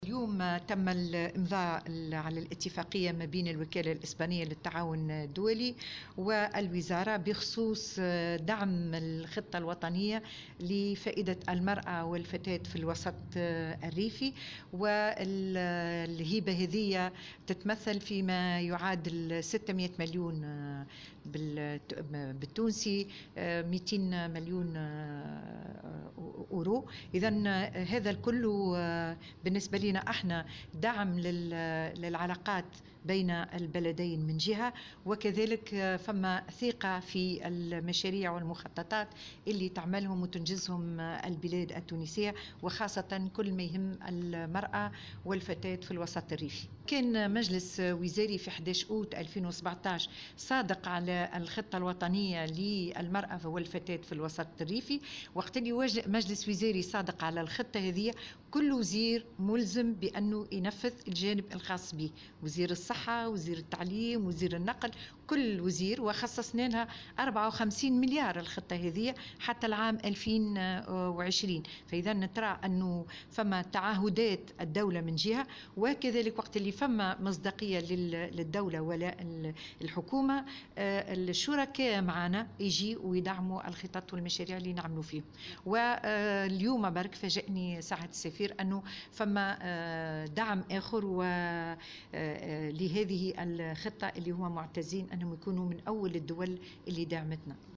وأكدت وزيرة المرأة والأسرة والطفولة وكبار السن، نزيهة العبيدي في تصريح لمراسلة "الجوهرة أف أم" أن هذه الهبة تقدر قيمتها بـ 600 ألف دينار وهي تأتي في اطار دعم الجهود الوطنية في مجال تنفيذ الاستراتيجية الوطنية للتمكين الاقتصادي و الاجتماعي للنساء و الفتيات في الوسط الريفي.